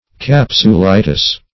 Search Result for " capsulitis" : The Collaborative International Dictionary of English v.0.48: Capsulitis \Cap`su*li"tis\, n. [NL.; E. capsule + -itis.]